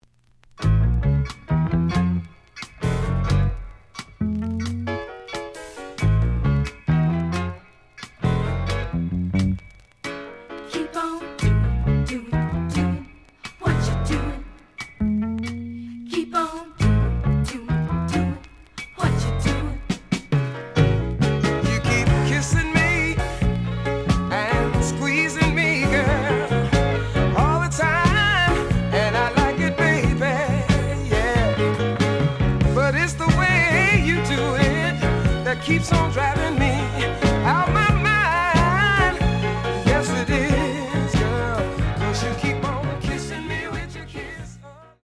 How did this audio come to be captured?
The audio sample is recorded from the actual item. Slight edge warp. But doesn't affect playing.